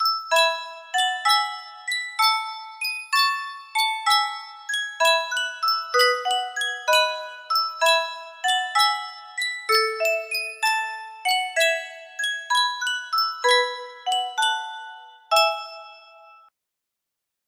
Sankyo Music Box - Sweet Hour of Prayer RFT music box melody
Full range 60